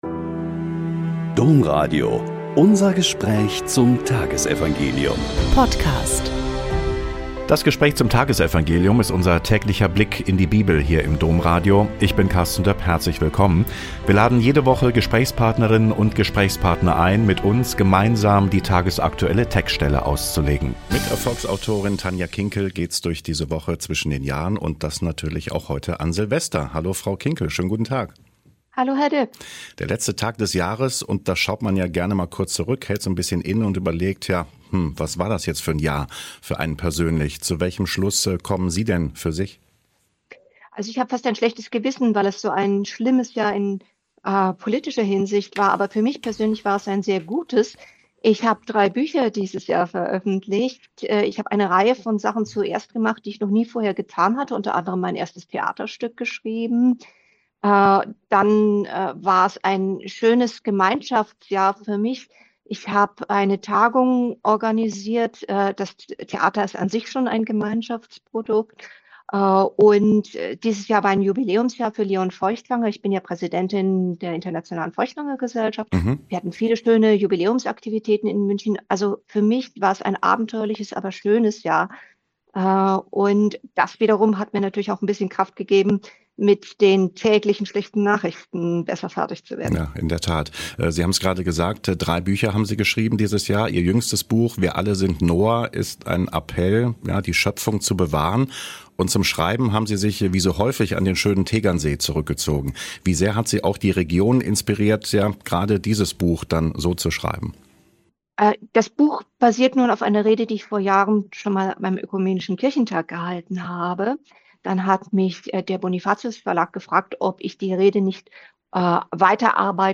Joh 1,1-18 - Gespräch mit Dr. Tanja Kinkel